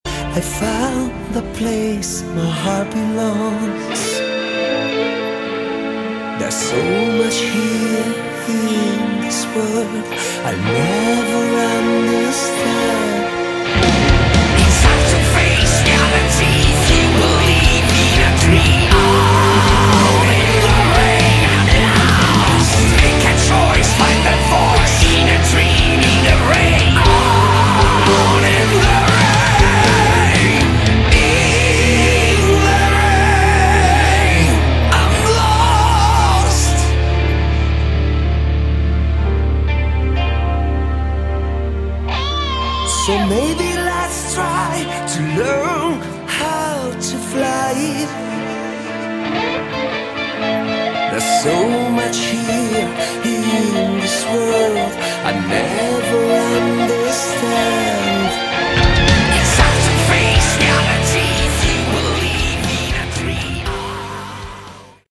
Category: Melodic/Power Metal